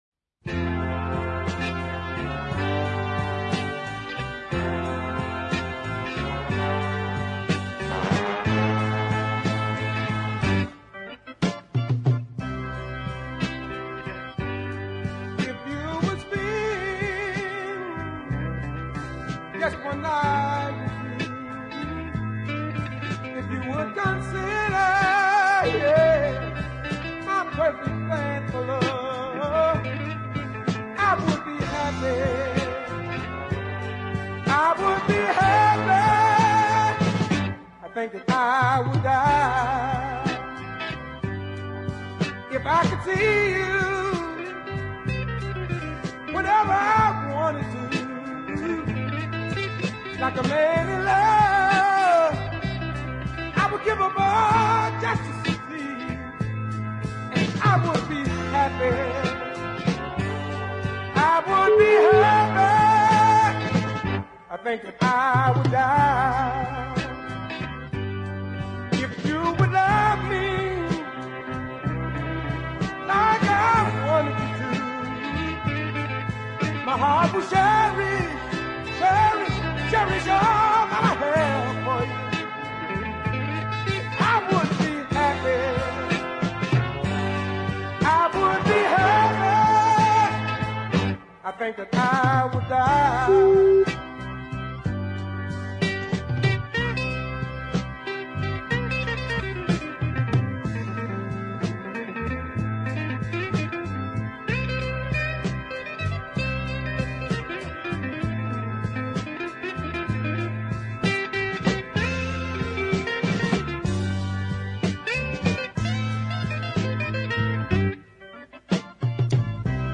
gruff tones